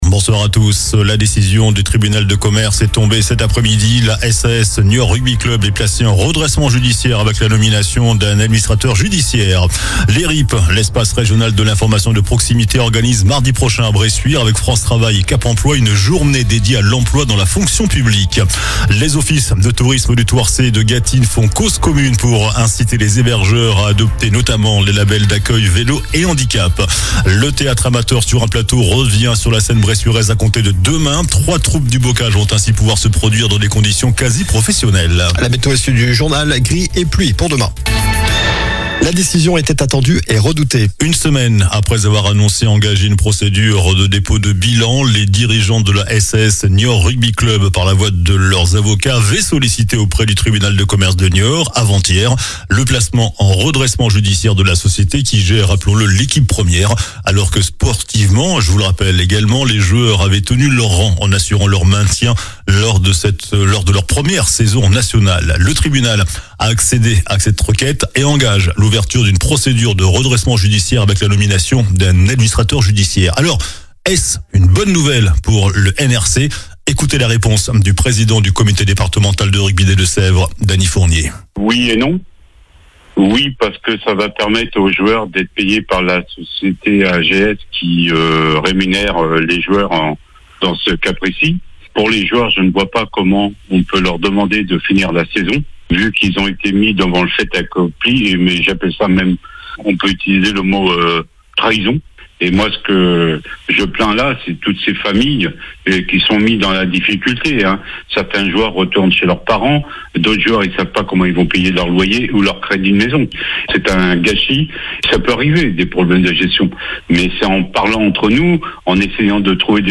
JOURNAL DU JEUDI 12 MARS ( SOIR )